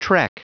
Prononciation du mot trek en anglais (fichier audio)
Prononciation du mot : trek